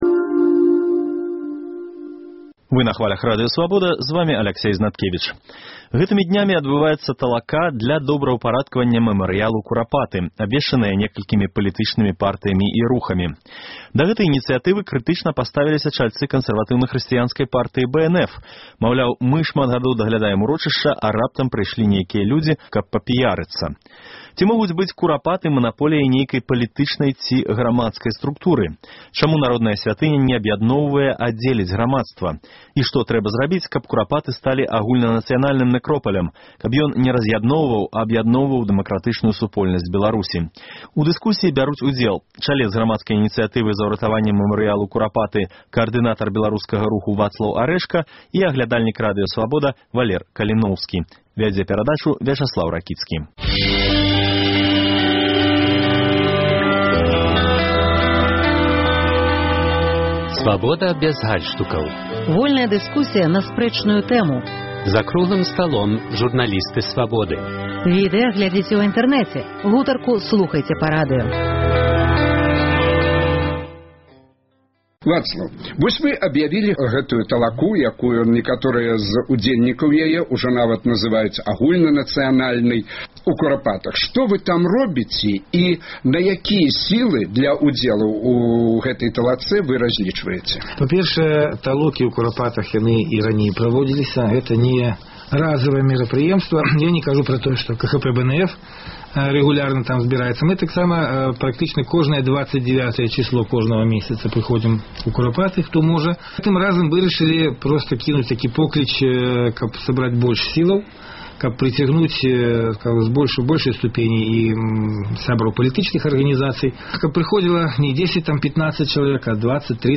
У дыскусіі бяруць удзел